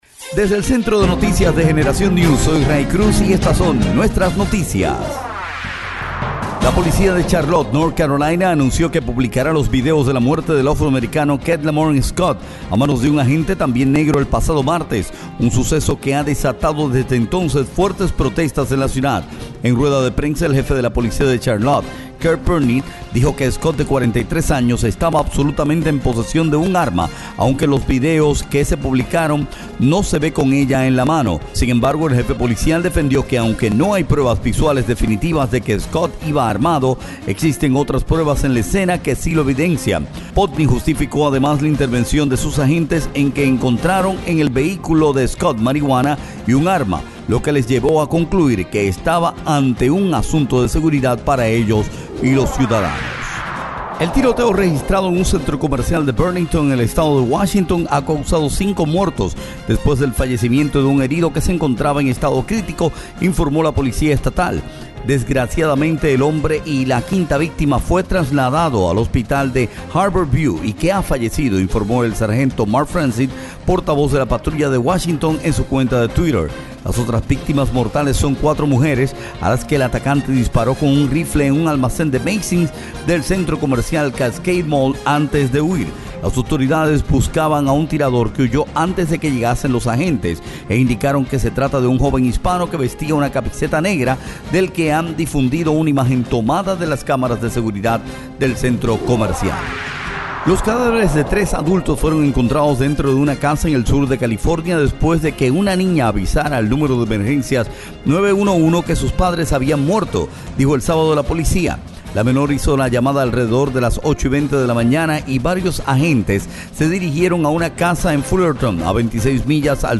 Resumen de Noticias 26 de sept